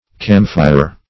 Camphire \Cam"phire\ (k[a^]m"f[imac]r), n.